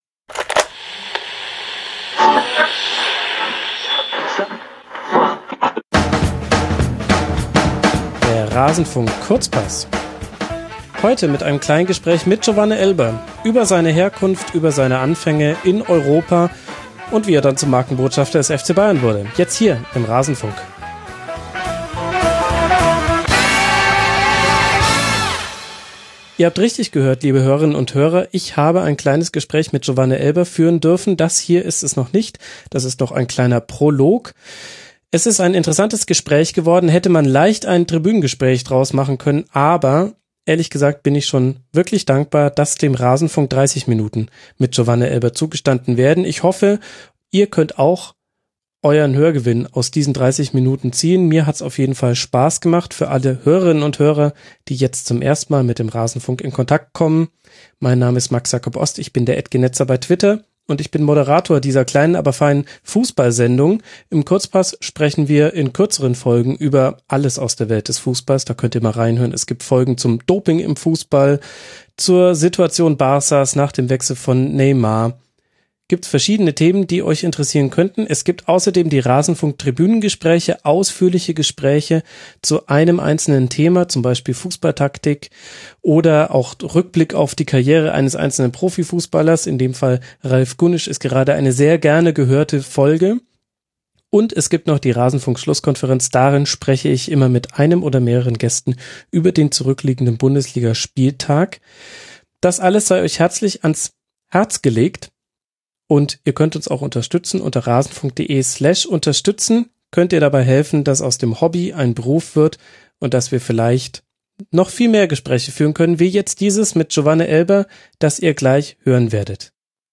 #23 Giovane Elber im Intervi… - Tribünengespräch - RASENFUNK